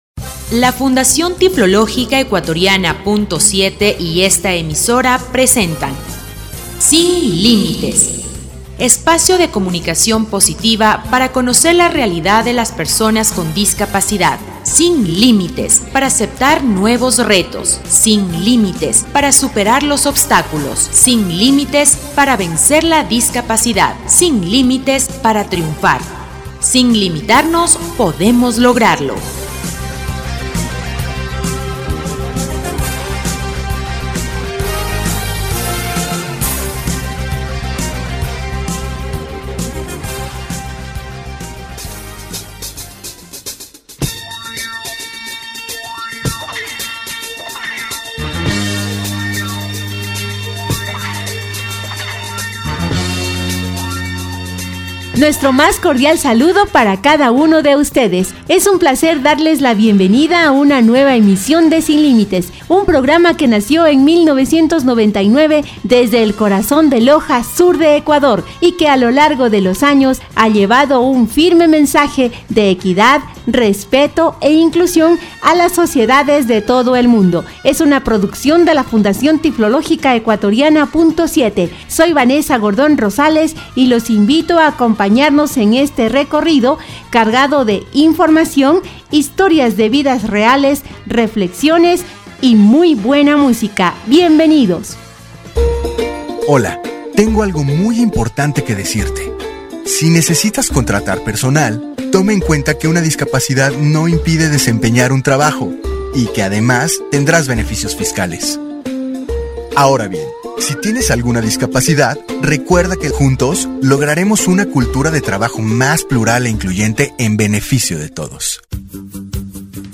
Espacio de comunicación positiva para conocer la realidad de las personas con discapacidad, disfruta de una nueva edición del programa radial «Sin Límites».